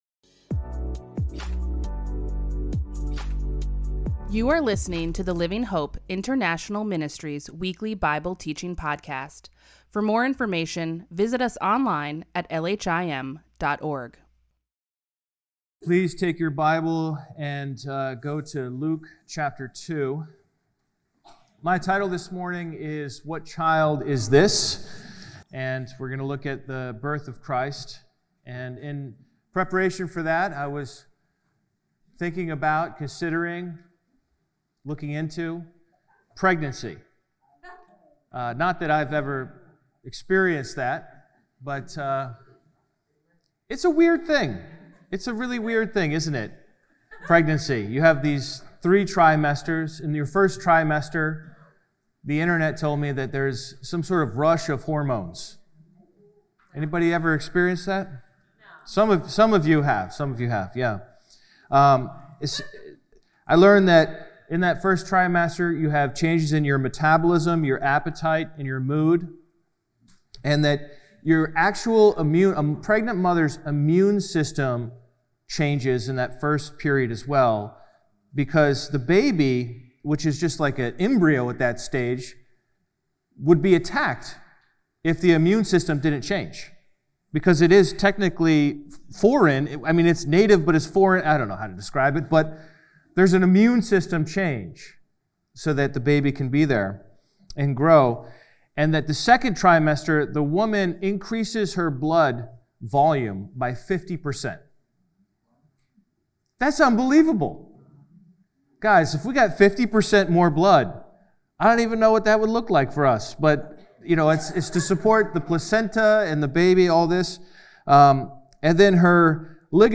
A weekly podcast featuring the Sunday Bible teachings of Living Hope International Ministries (LHIM) in Latham, NY.